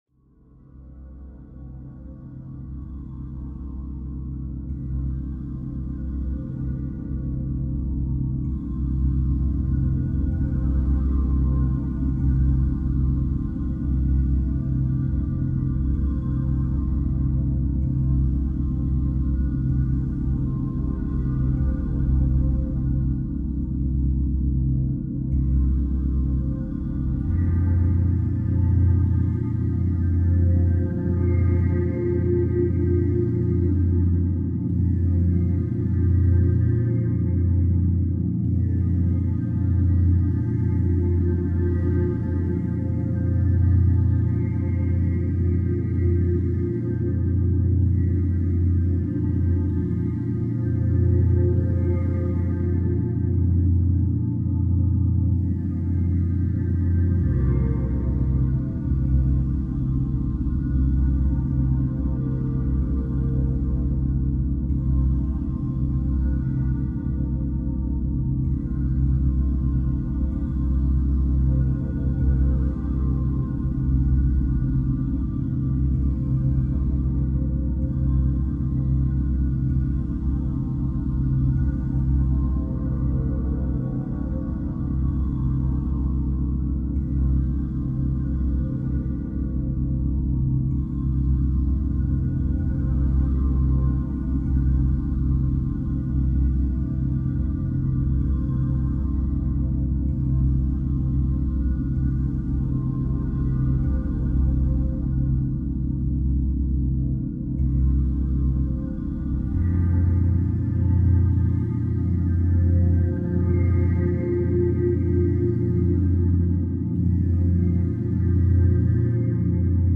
Schumann Resonance Meditation – 6 Earth Tones for Grounding